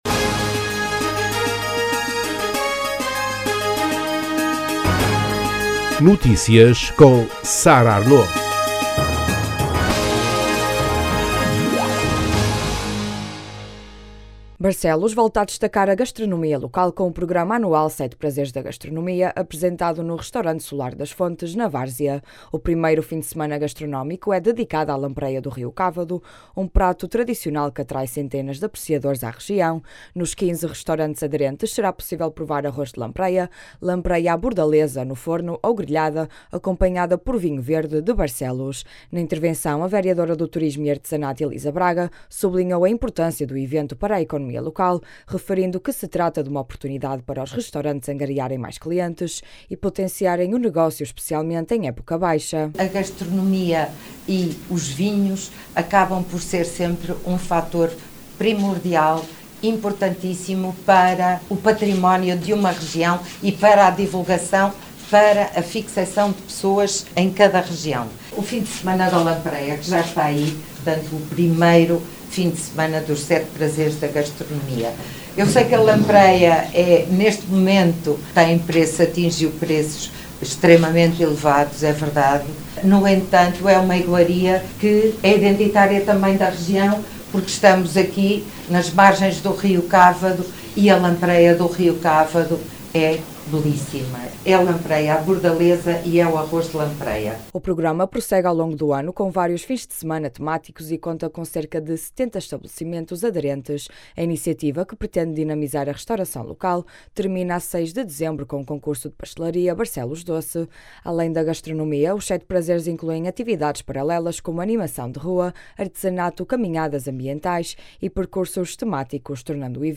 Na intervenção, a vereadora do turismo e artesanato, Elisa Braga, sublinhou a importância do evento para a economia local, referindo que se trata de uma oportunidade para os restaurantes angariarem mais clientes e potenciarem o negócio, especialmente em época baixa.